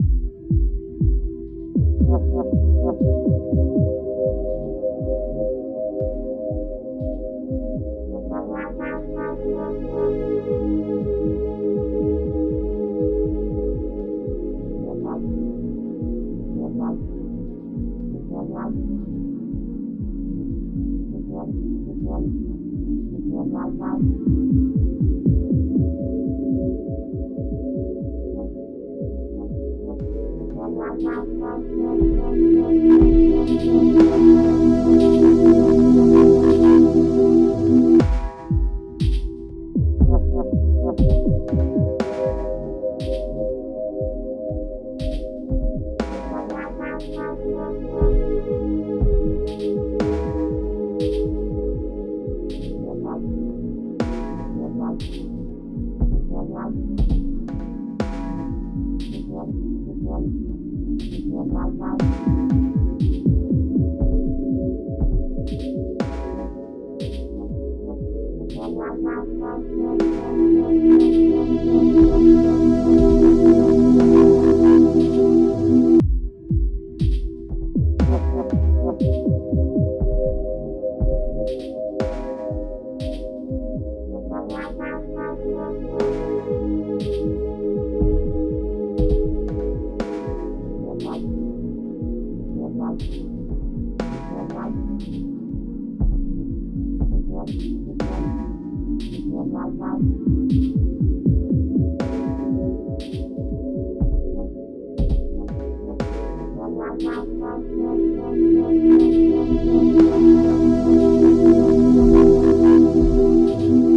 ambient music